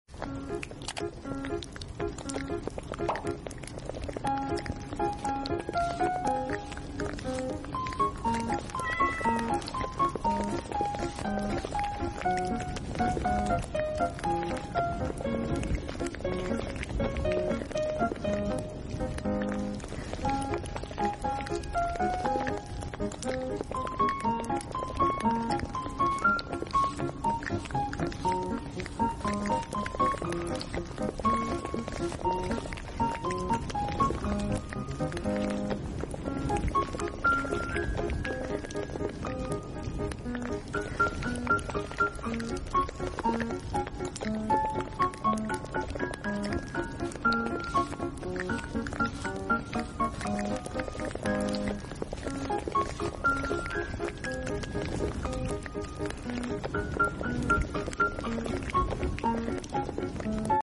cafe sound 🧂🎀 sound effects free download